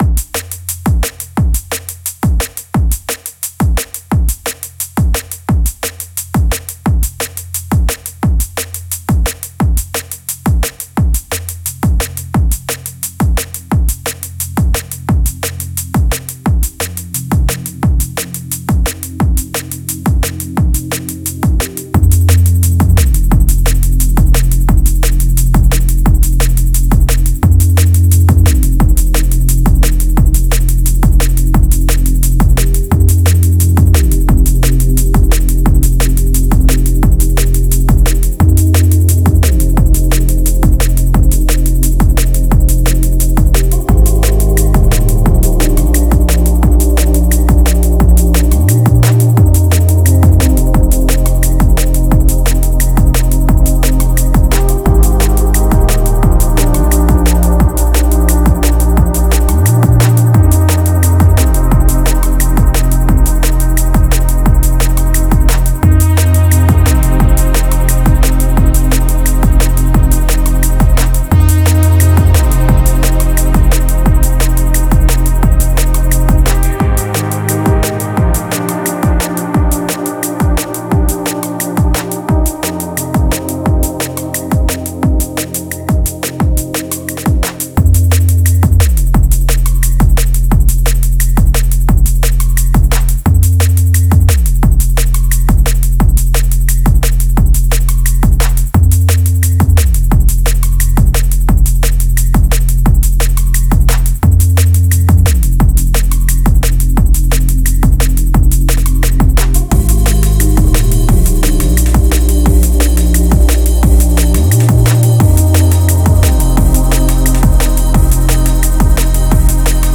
Стиль: Drum & Bass